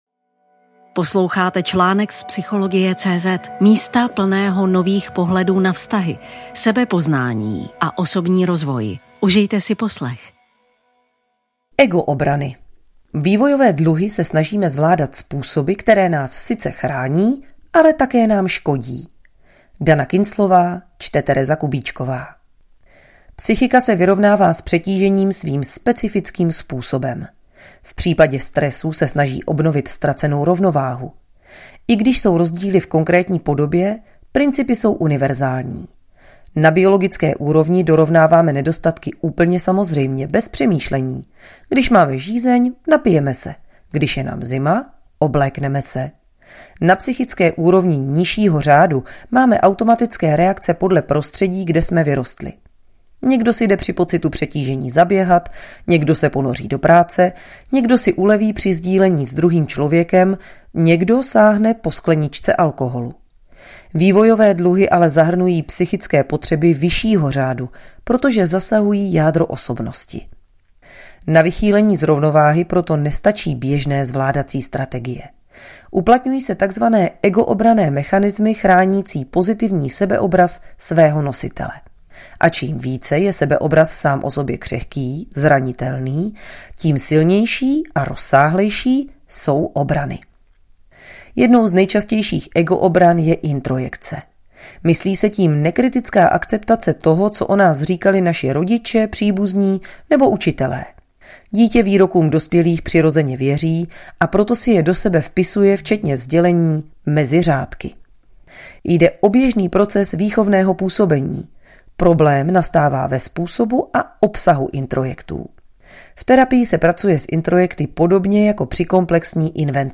Audioverze článku